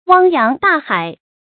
汪洋大海 wāng yáng dà hǎi
汪洋大海发音
成语正音 大，不能读作“dài”。